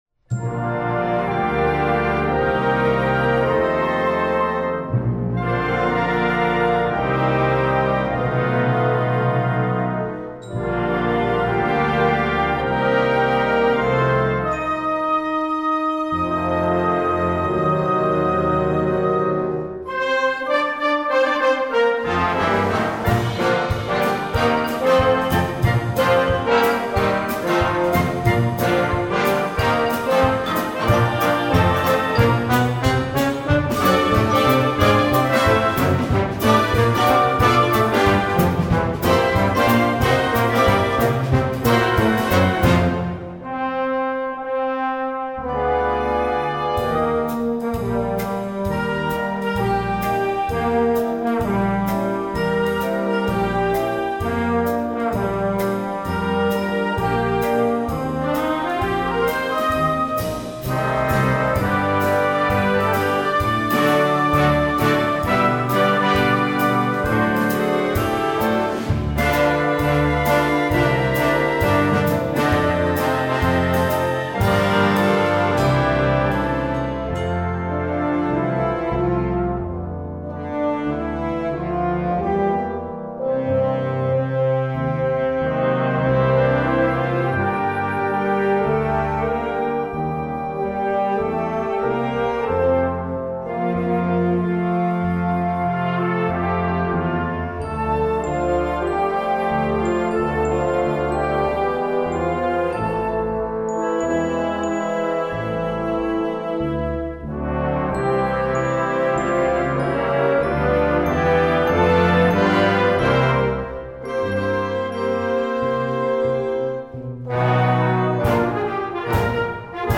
Konzertante Blasmusik
Besetzung: Blasorchester